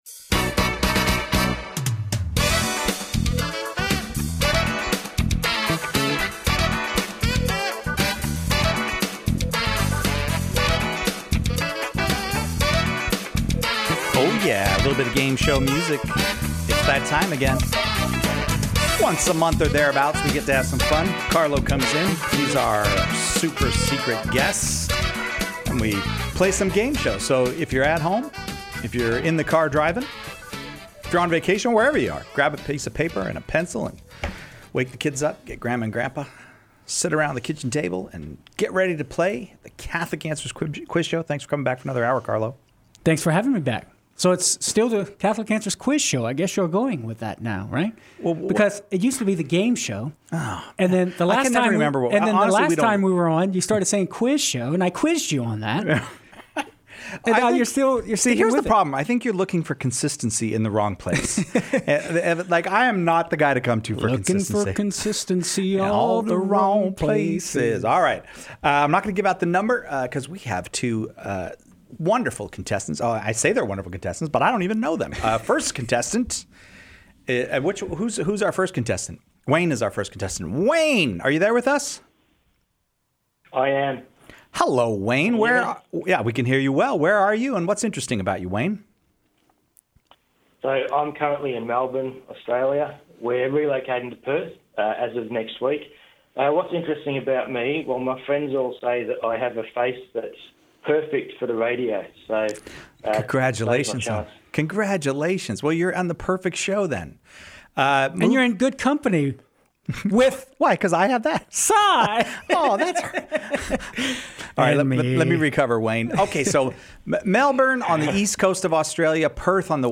Catholic Game Show